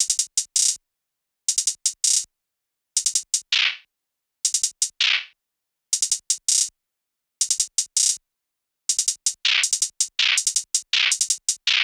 SOUTHSIDE_beat_loop_bread_hihat_01_162.wav